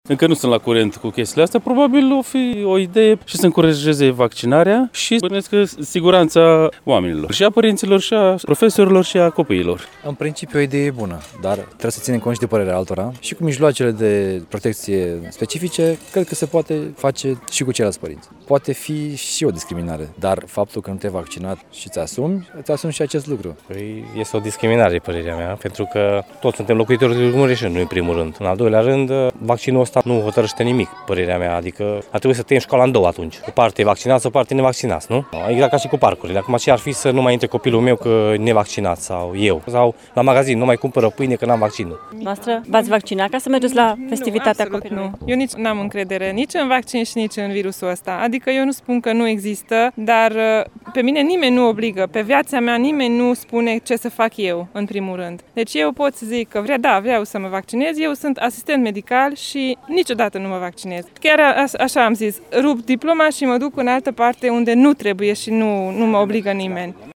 Părerea părinților este, însă, împărțită în această privință: